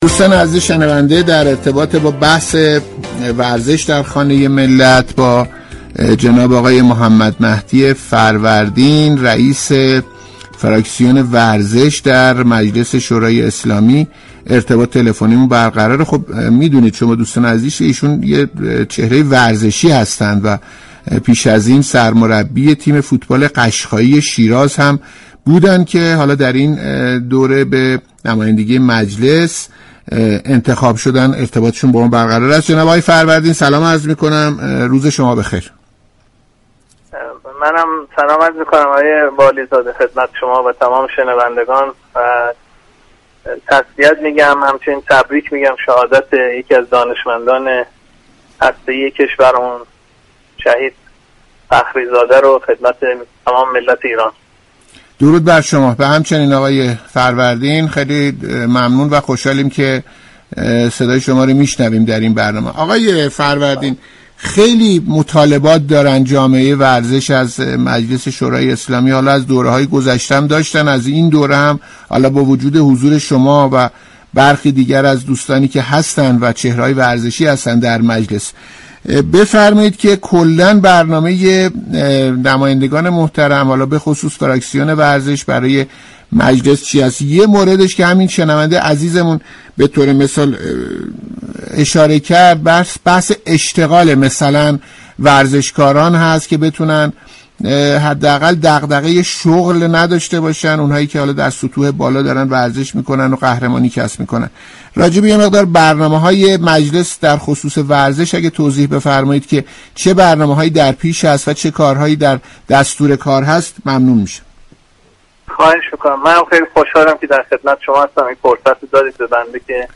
برنامه ورزش و جامعه رادیو ورزش شنبه 8 آذر با حضور محمدمهدی فروردین، رئیس فراكسیون ورزش در مجلس شورای اسلامی به موضوع تغییر بودجه 1400 و خبرهای خوش برای ورزشی ها پرداخت.